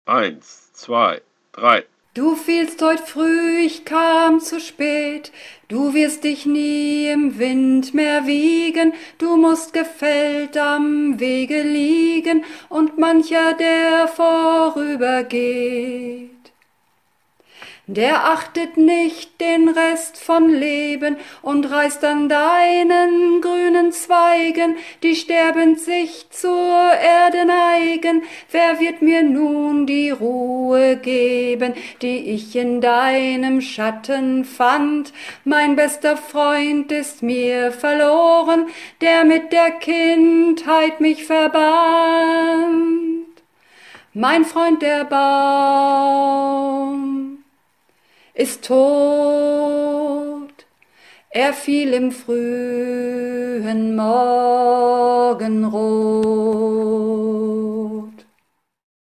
Übungsaufnahmen - Mein Freund, der Baum
Runterladen (Mit rechter Maustaste anklicken, Menübefehl auswählen)   Mein Freund, der Baum (Sopran-Alt 2)
Mein_Freund_der_Baum__Sopran-Alt2.mp3